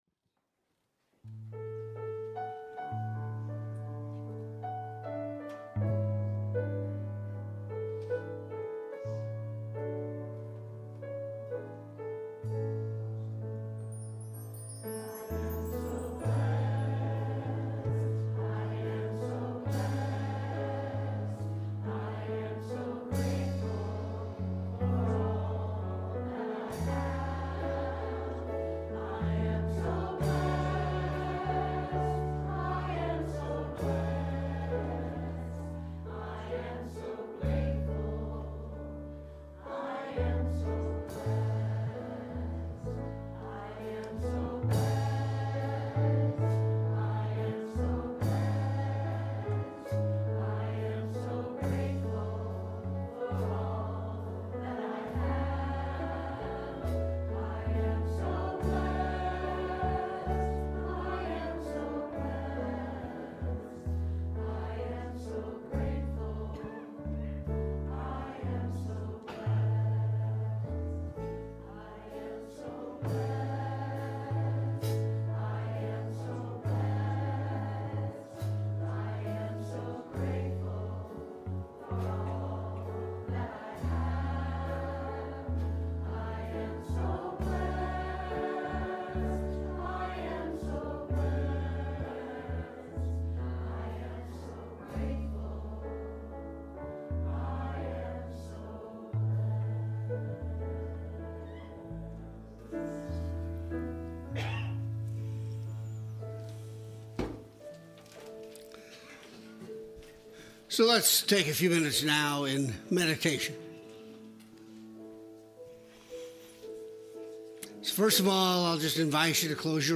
The audio recording (below the video clip) is an abbreviation of the service. It includes the Meditation, Lesson and Featured Song.